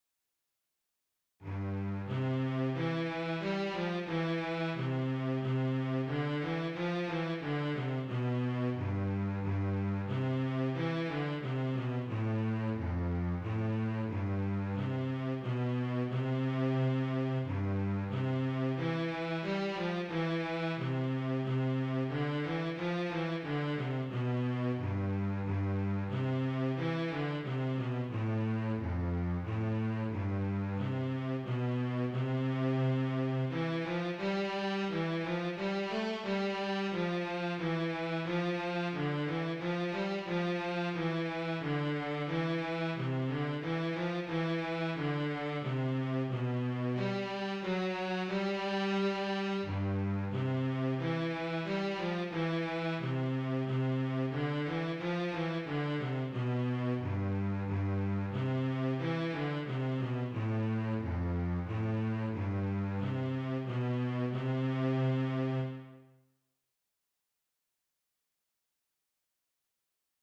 DIGITAL SHEET MUSIC - CELLO SOLO